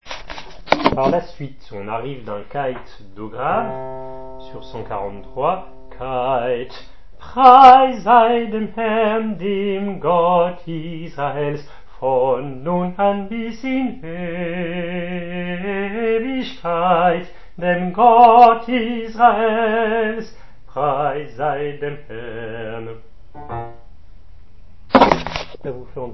134 à 140 lent